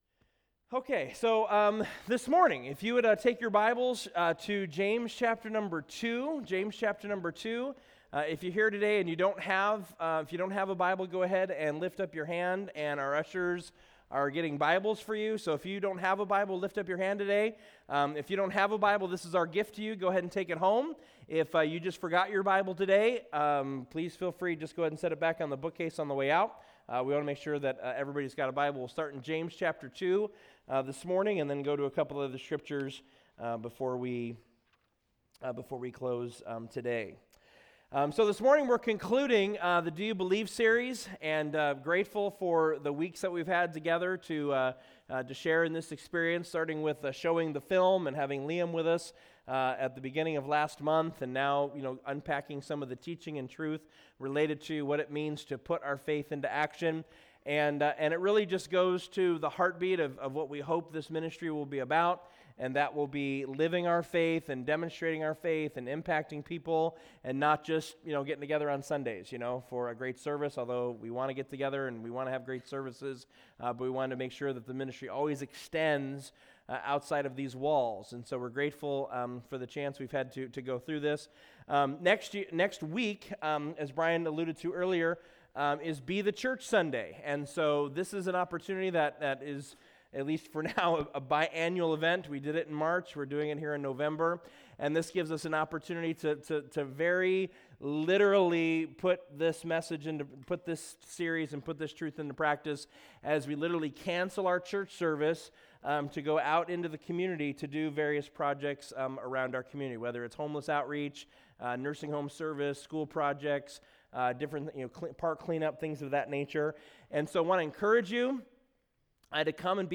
James 2:26 Service Type: Weekend Services Bible Text